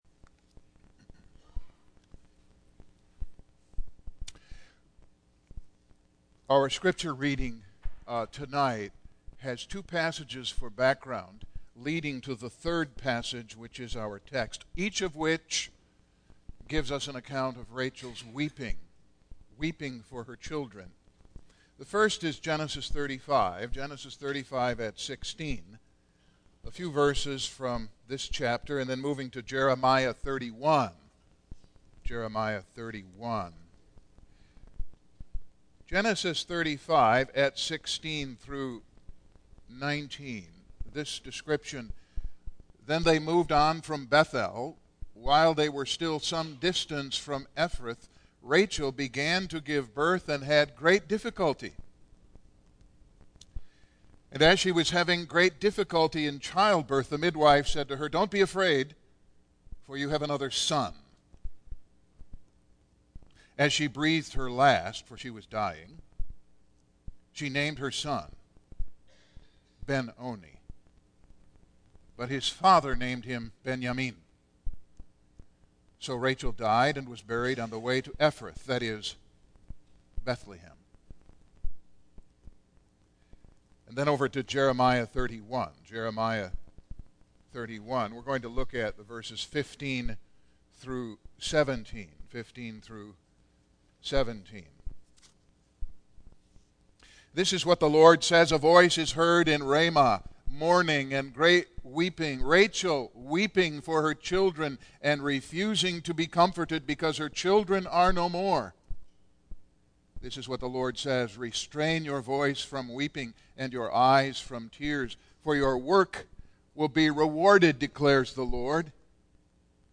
Single Sermons Passage: Genesis 35:16-19, Jeremiah 31:15-17, Matthew 2:16-18 %todo_render% « Immanuel